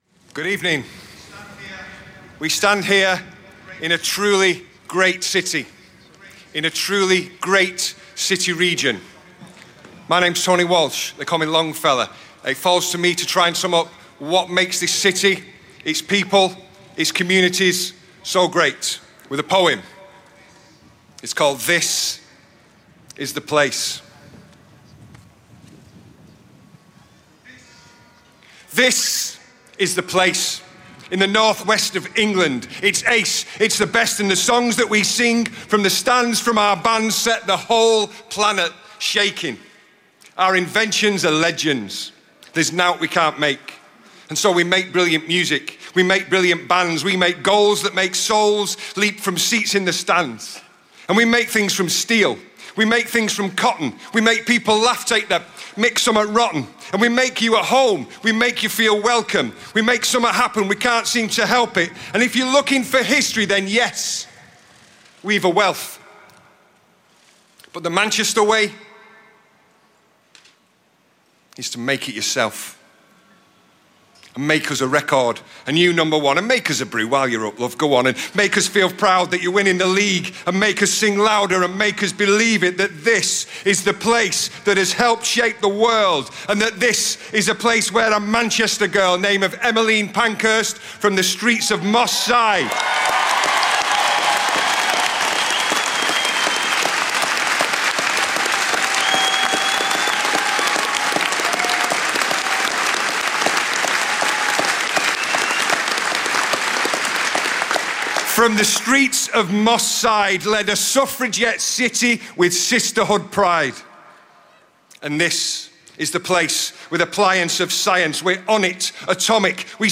Tony Walsh – This . . .Is The Place – Poem given at Manchester Bombing vigil – May 23, 2017 – BBC5 Live –